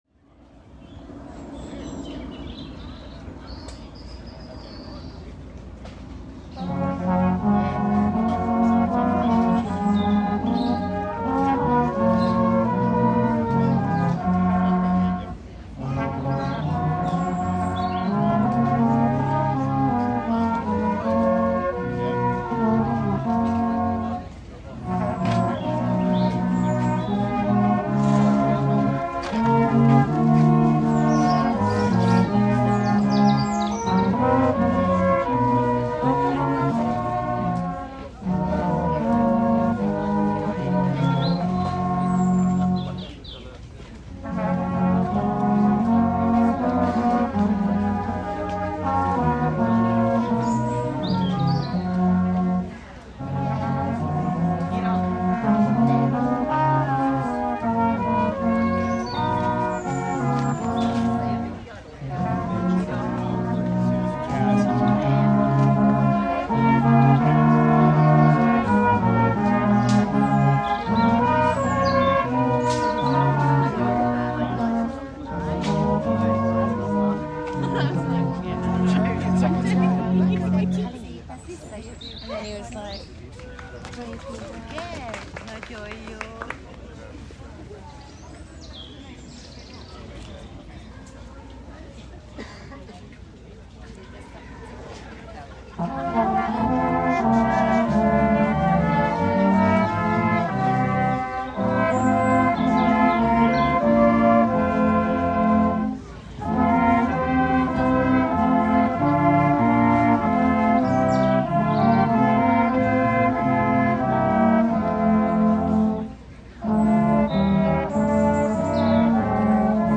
Field Recordings podcastit
Viimeksi kuunneltu Christmas brass and birds, London, UK on 15th December 2024
Brass-in-Park.mp3